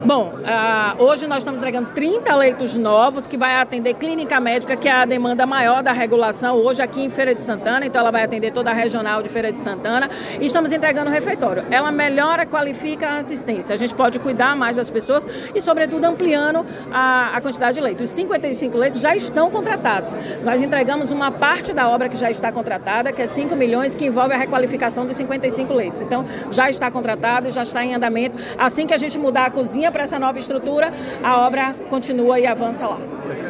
Secretária Roberta Santana, no Hospital Geral Cleriston Andrade, em Feira de Santana